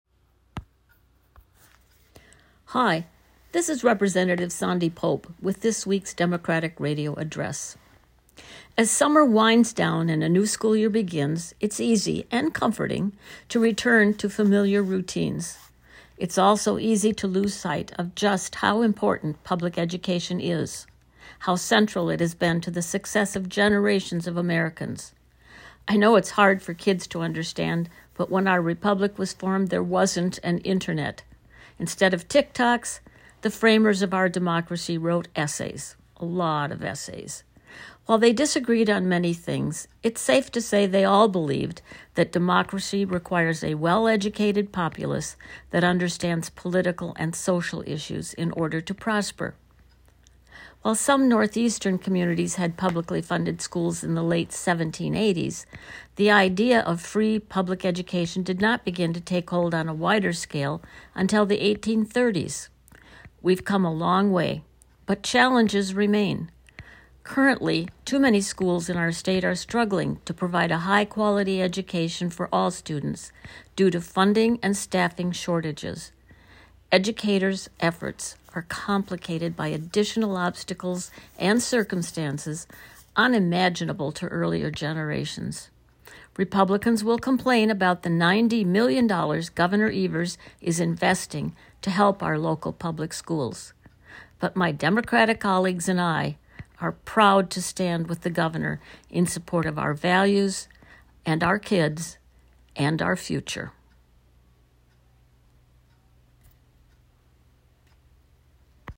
Weekly Dem radio address: Rep. Pope touts Gov. Evers' $90 million investment in K-12 schools - WisPolitics